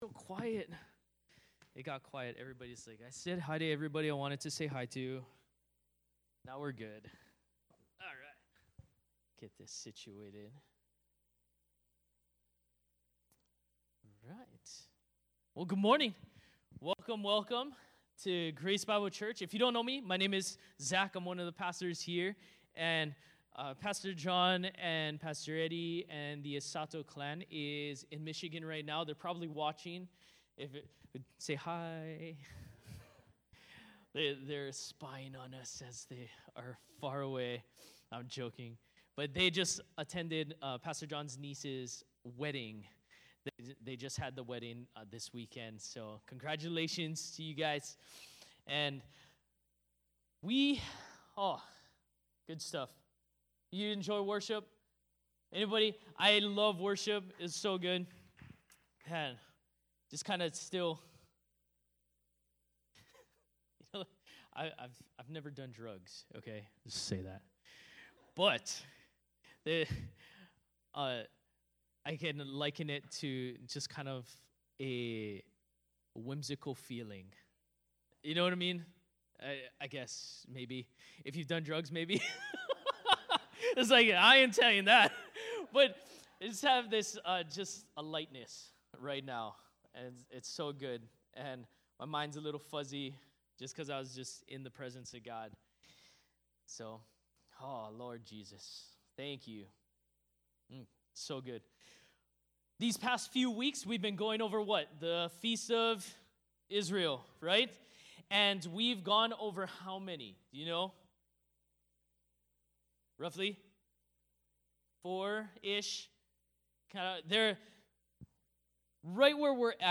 Join our pastors each week for a new sermon from Grace Bible Church Maui.